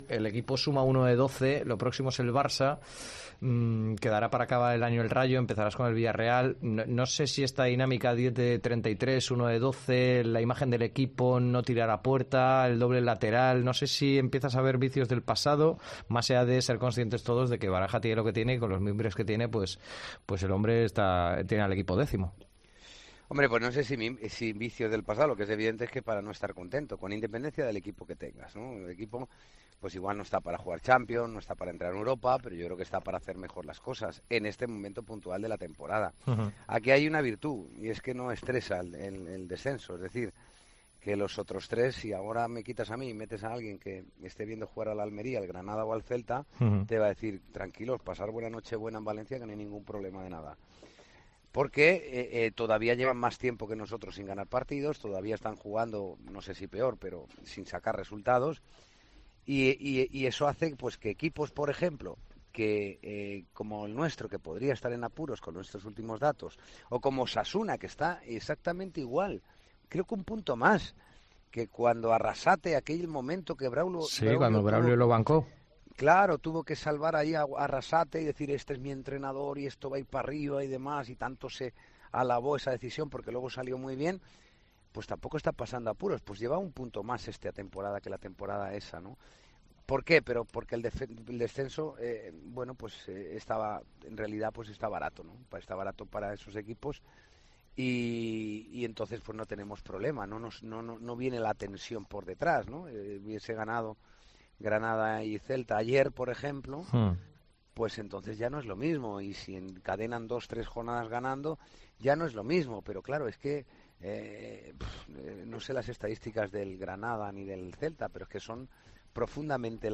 Santiago Cañizares ha pasado por los micrófonos de Deportes COPE Valencia para analizar el momento de forma y de juego del equipo de Rubén Baraja y el enfoque antes de que se abra el mercado de fichajes de invierno.